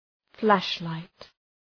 Shkrimi fonetik {‘flæʃ,laıt}